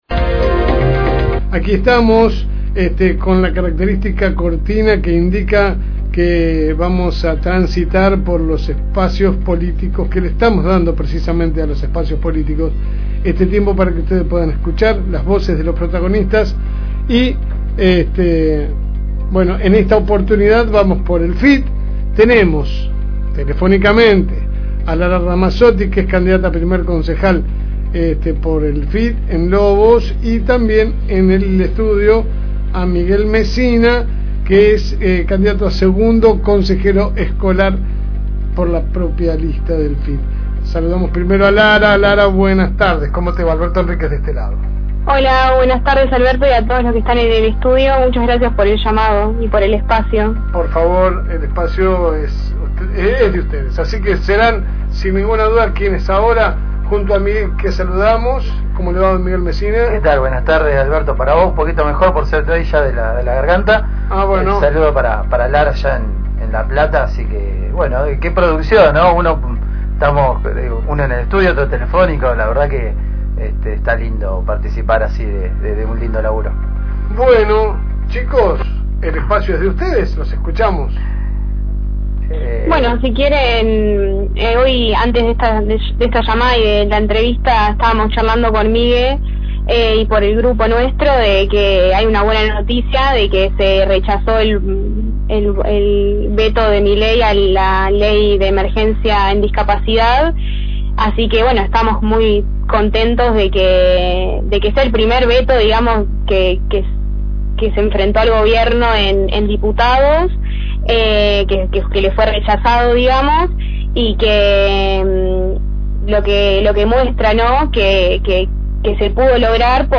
Espacio Electoral.